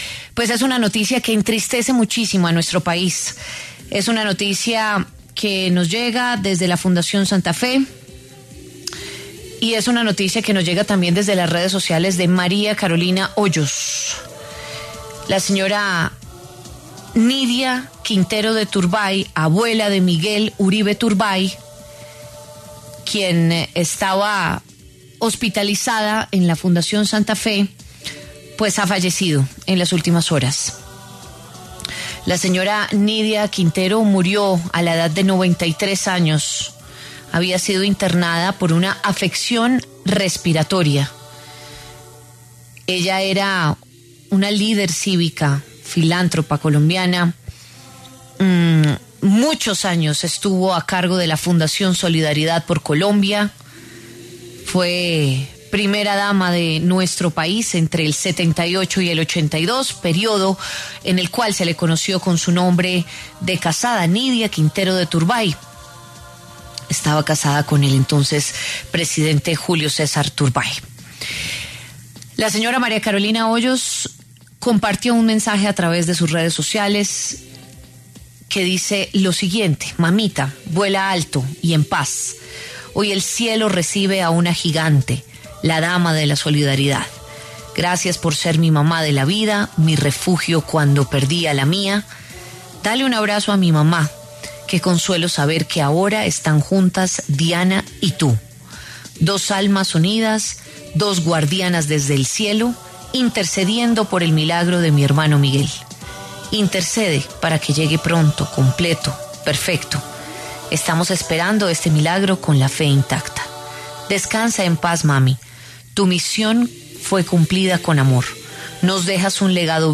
La W conversó con Nydia Quintero en varias oportunidades.
Entrevista Nydia Quintero